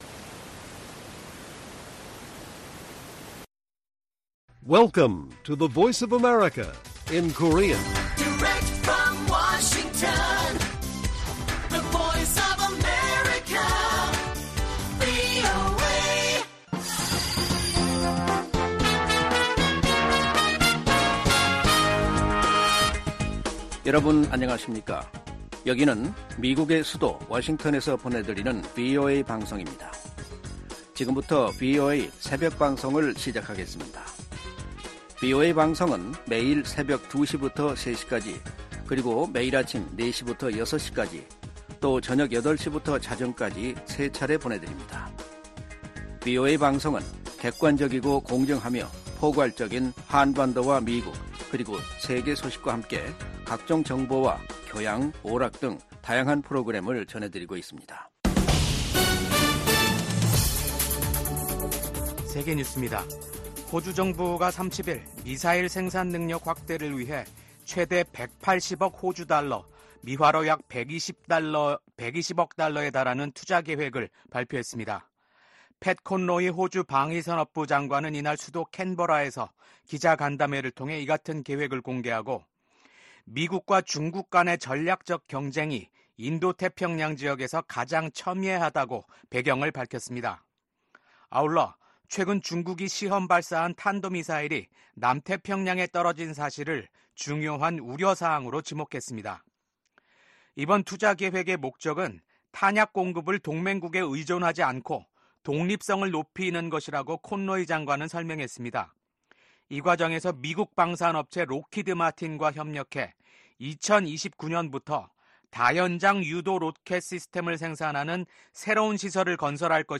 VOA 한국어 '출발 뉴스 쇼', 2024년 10월 31일 방송입니다. 미국 국방부는 러시아에 파견된 북한군 일부가 이미 우크라이나에 가까운 러시아 쿠르스크에 주둔 중이라고 밝혔습니다. 윤석열 한국 대통령은 볼로디미르 젤렌스키 우크라이나 대통령과 쥐스탱 트뤼도 캐나다 총리와 연이어 통화를 하고 북한의 러시아 파병에 대한 공동 대응 의지를 밝혔습니다.